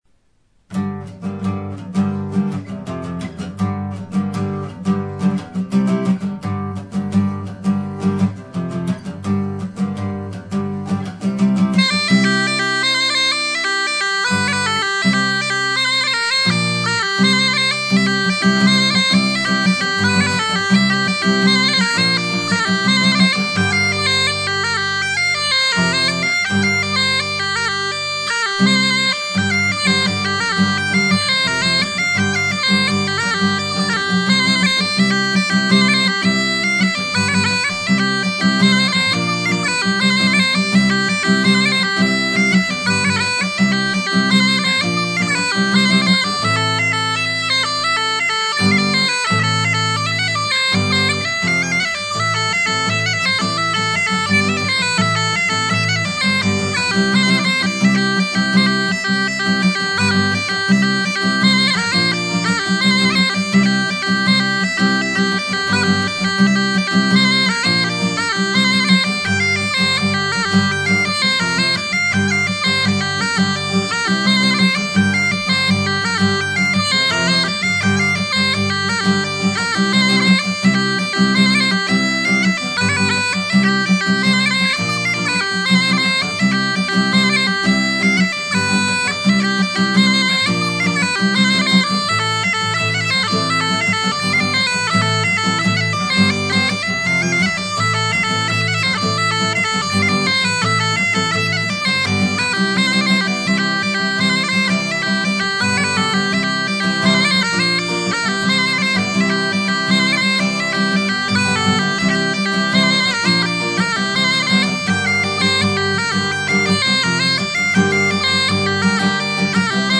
cornemuse
Café des Arts (Grenoble)